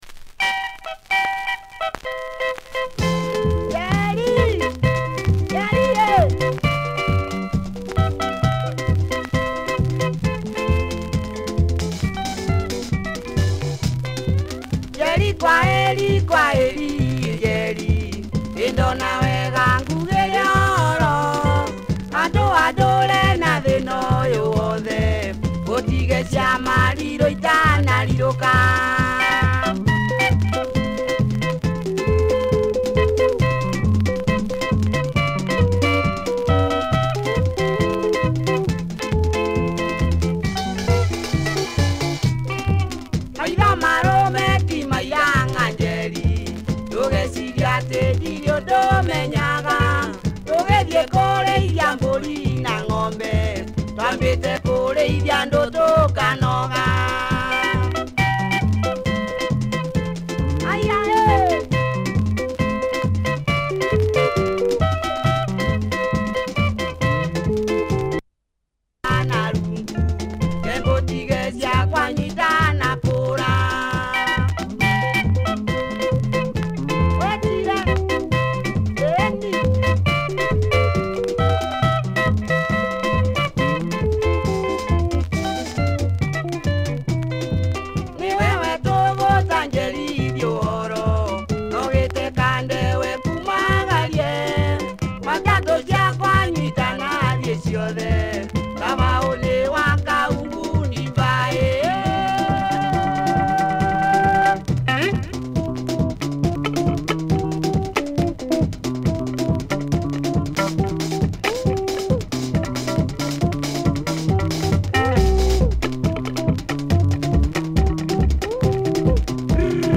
Always a surprise breadown near the end!